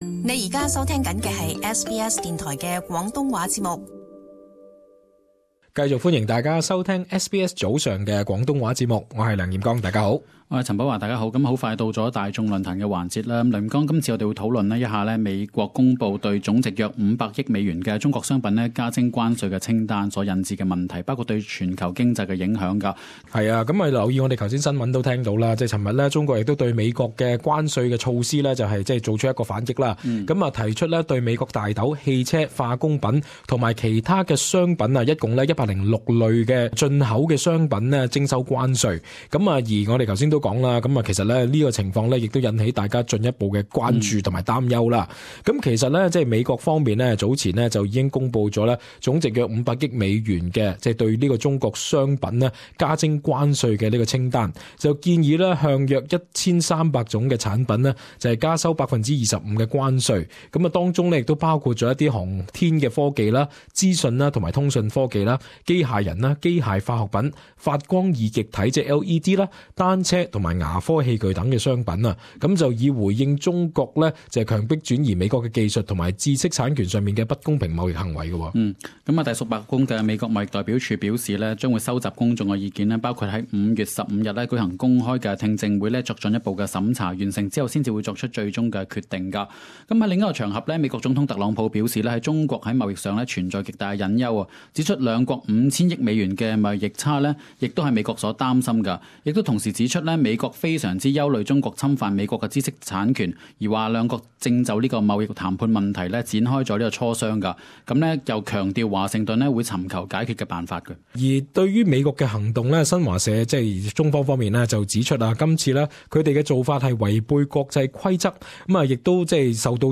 與聽眾朋友一齊探討此話題。